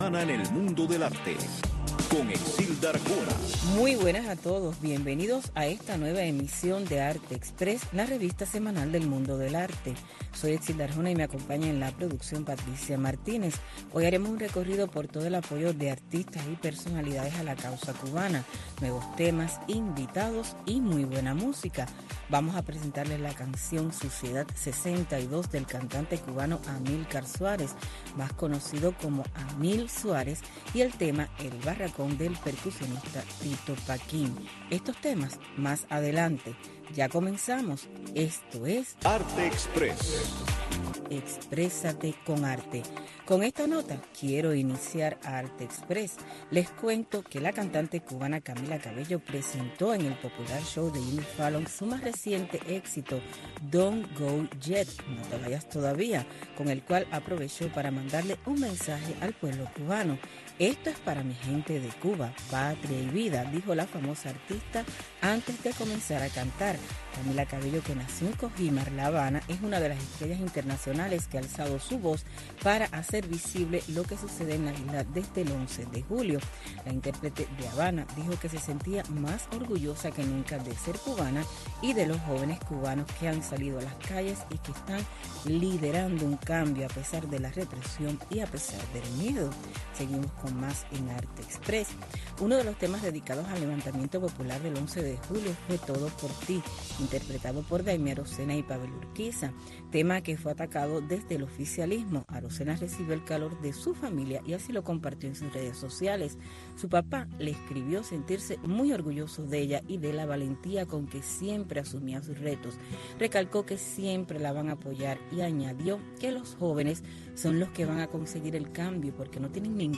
Arte Express, una revista informativa - cultural con noticias, eventos, blogs cubanos, segmentos varios, efemérides, música y un resumen de lo más importante de la semana en el mundo del arte.